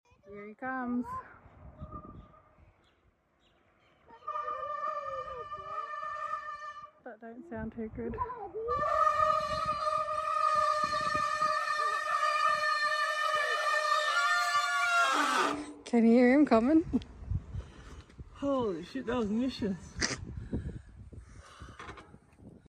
Big Rig Sound Effects Free Download.